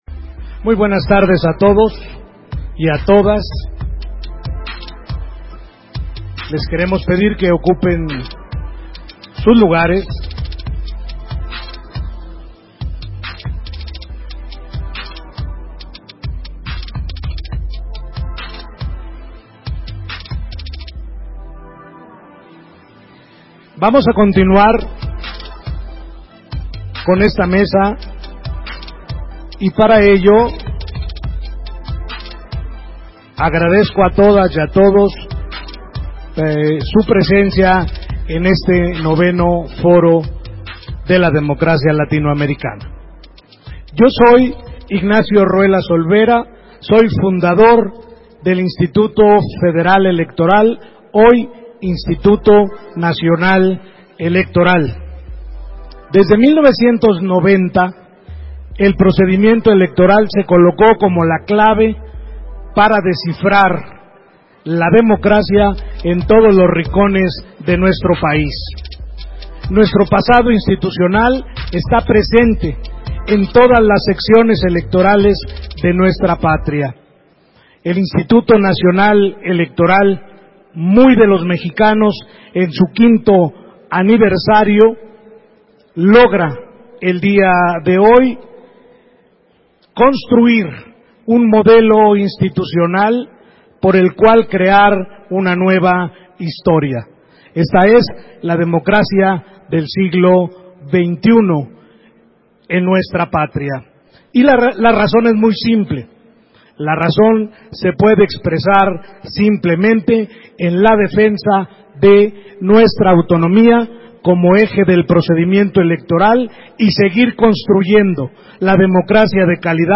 «190404_1324.mp3» de TASCAM DR-05.